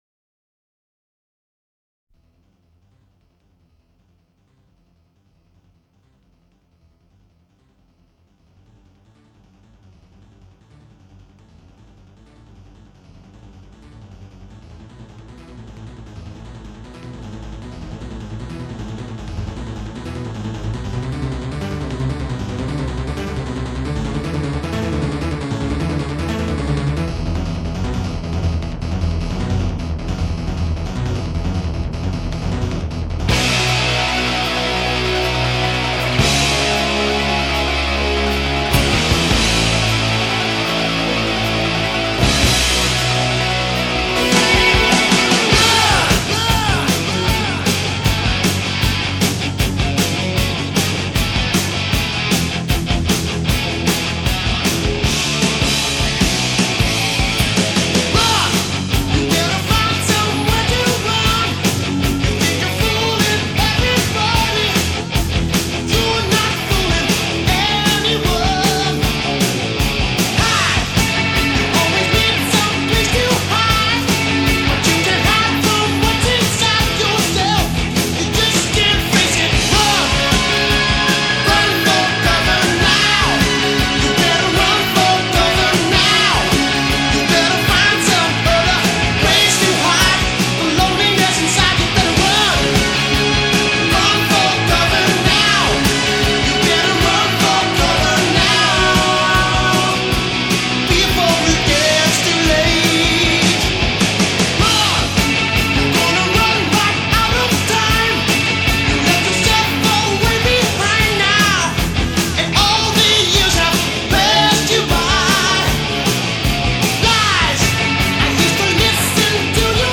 Hard Rock, Heavy Metal